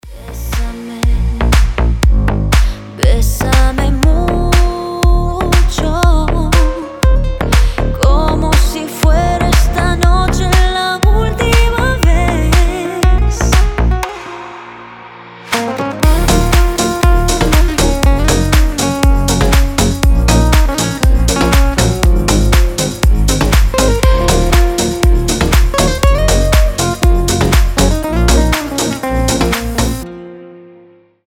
гитара
deep house
мелодичные
retromix
женский голос
Cover